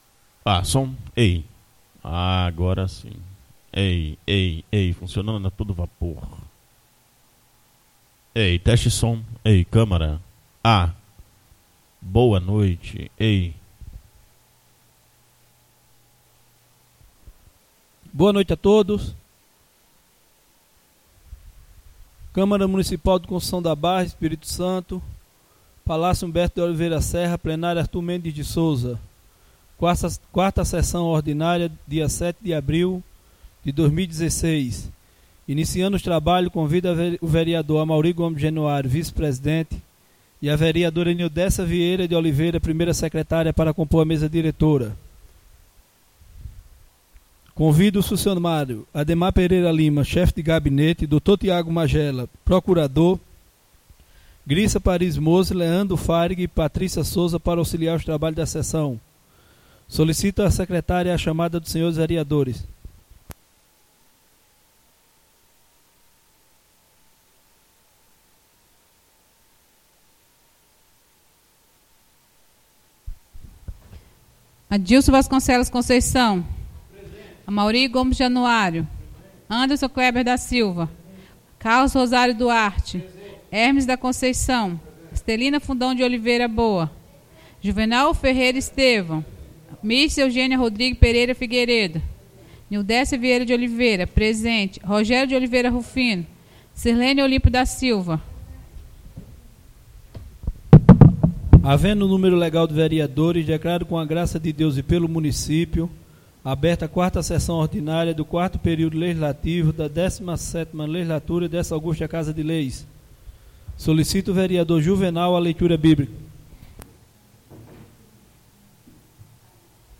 4ª (QUARTA) SESSÃO ORDINÁRIA